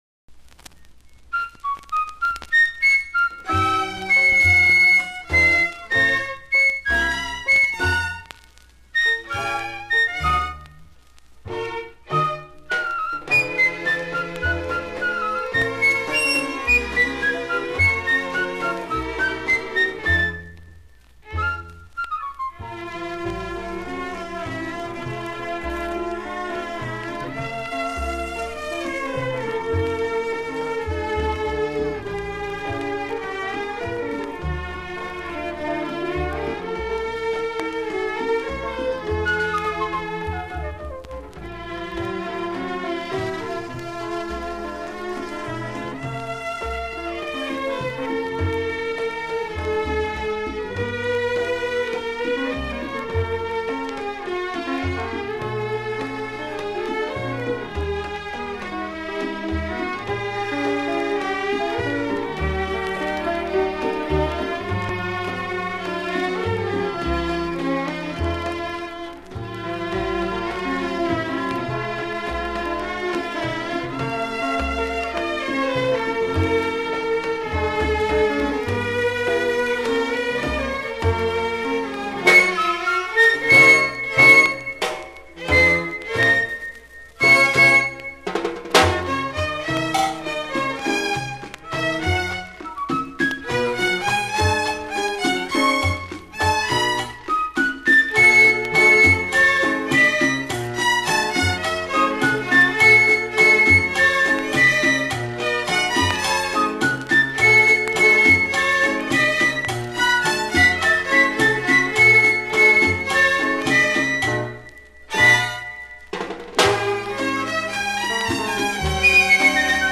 キューバ出身のピアニスト。６０年代キューバのダンソン/CHA CHA CHA !!!